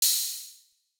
Open Hat (1).wav